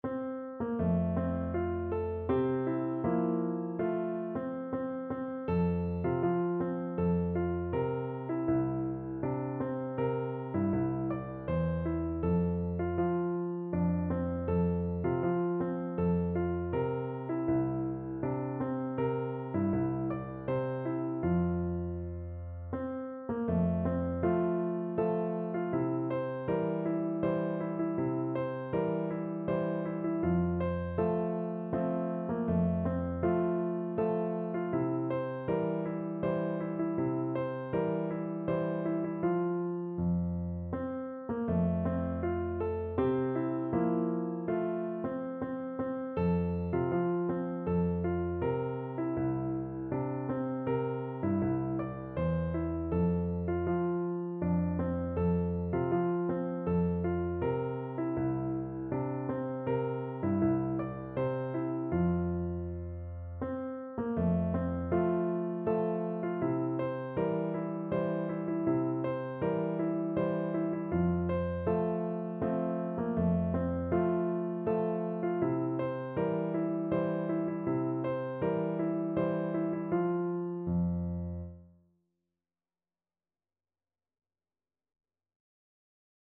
Piano version
solo piano